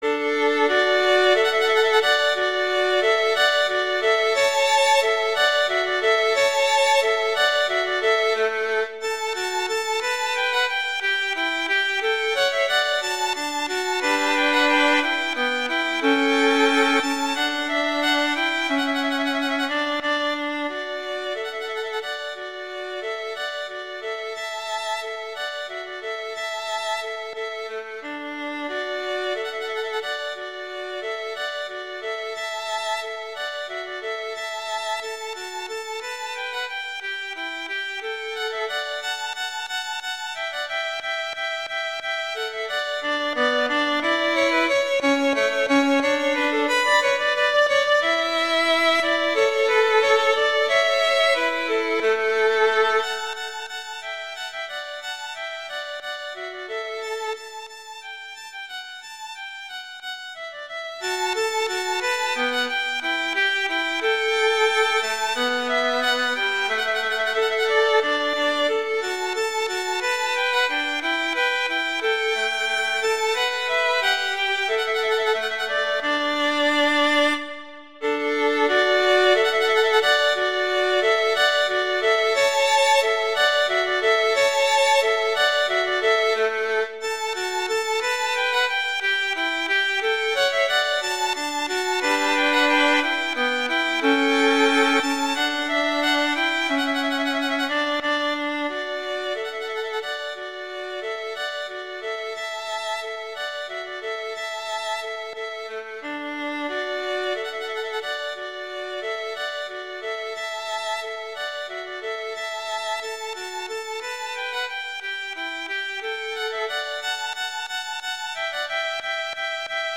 Instrumentation: two violins
classical, wedding, festival, love
D major
♩=180 BPM (real metronome 176 BPM)
Nice arrangement for two violins.